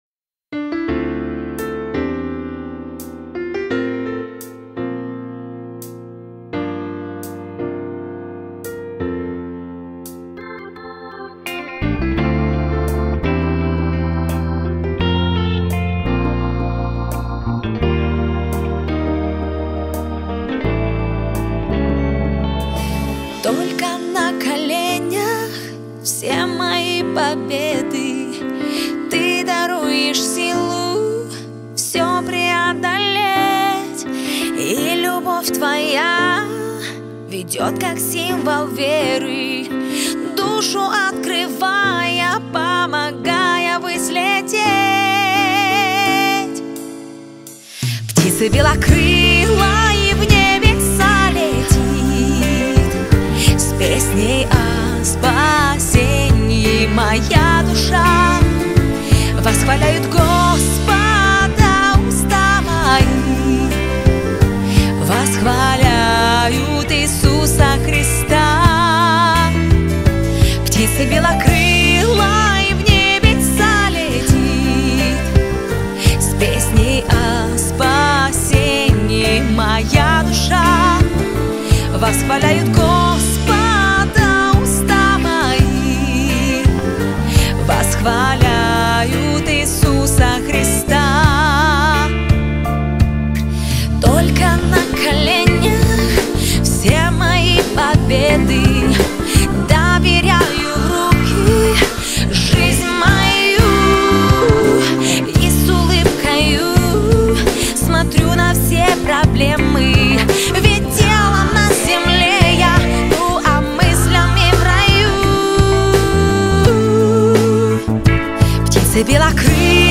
271 просмотр 222 прослушивания 21 скачиваний BPM: 170